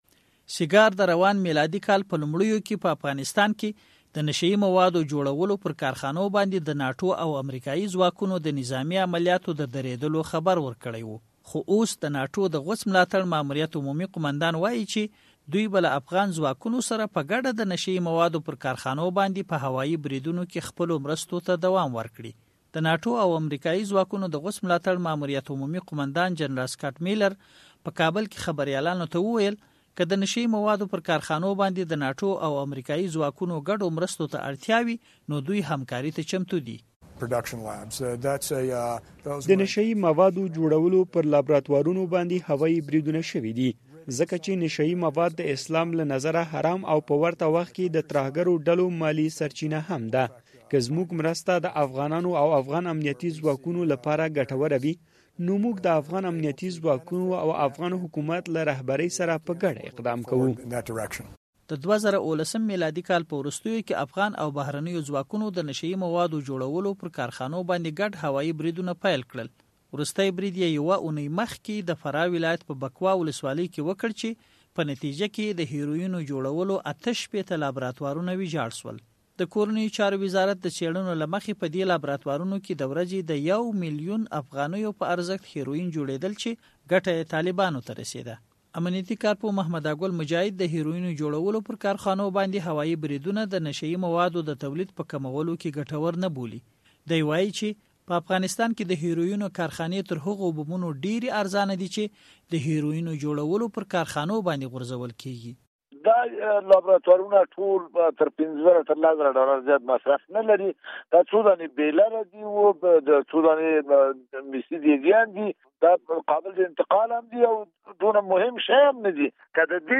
د زهرو کاروان غږیز راپور